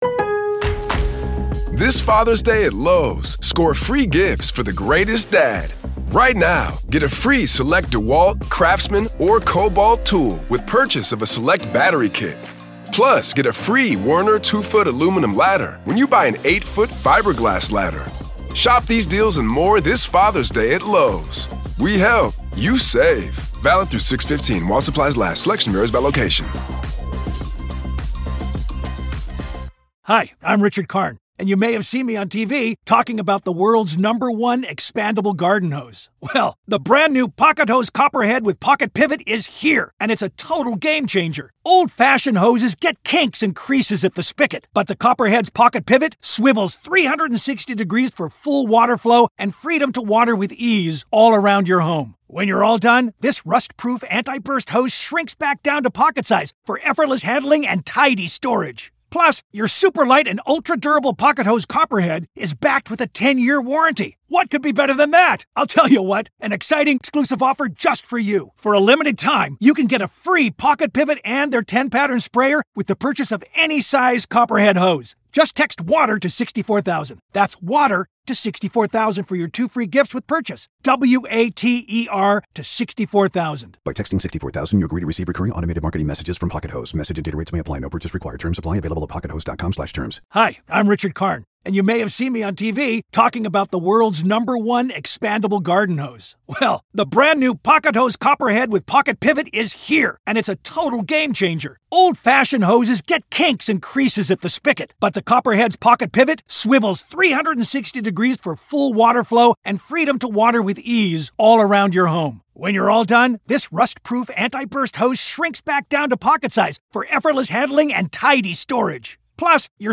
True Crime News & Interviews